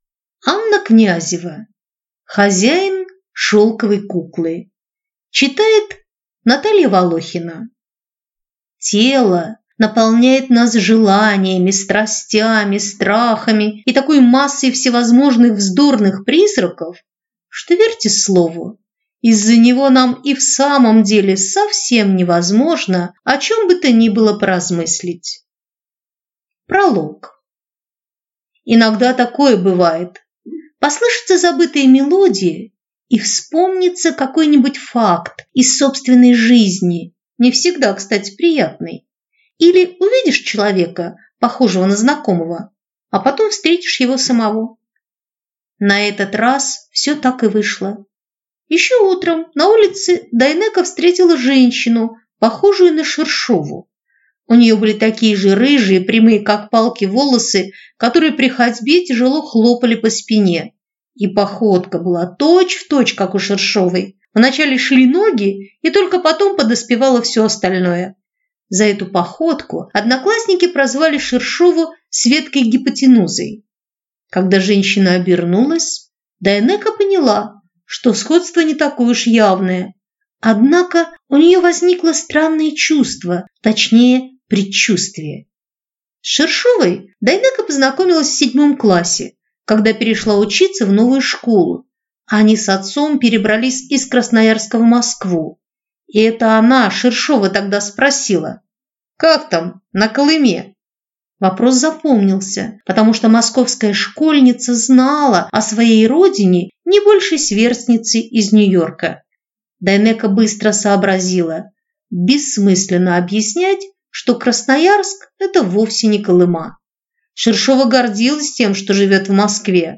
Аудиокнига Хозяин шелковой куклы | Библиотека аудиокниг